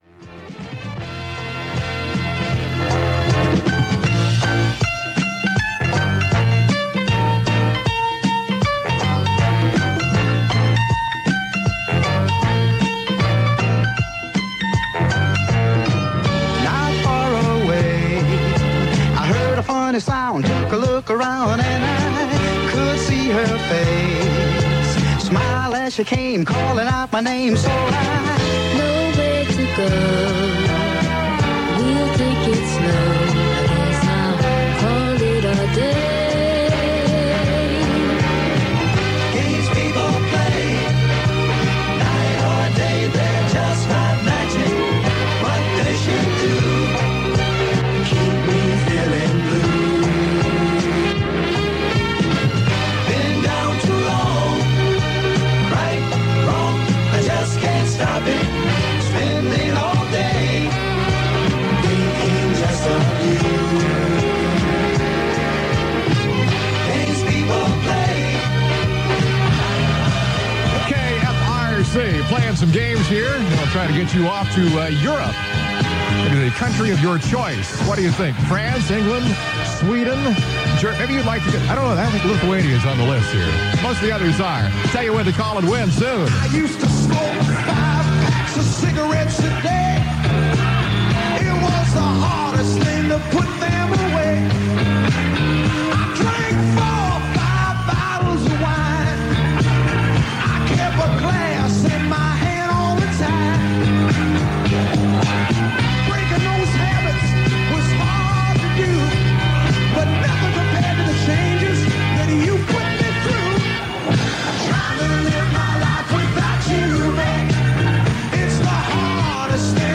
Your radio is more lively than usual.